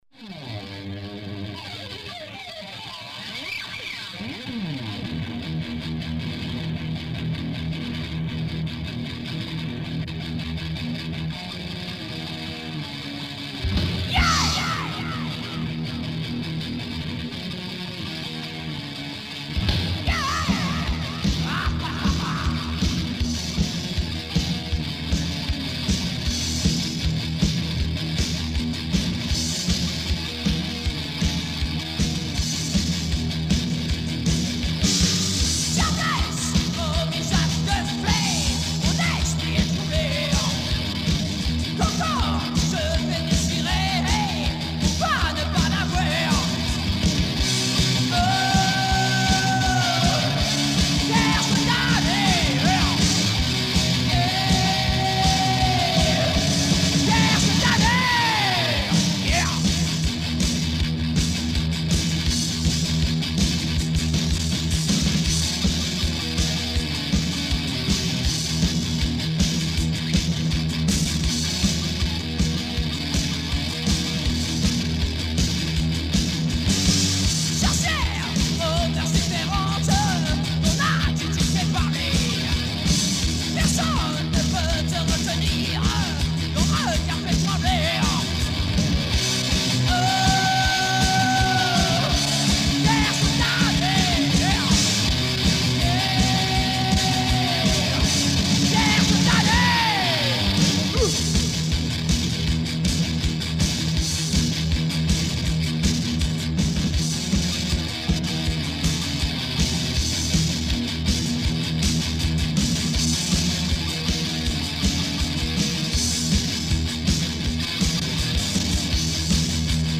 Du heavy sinon rien n’est-ce pas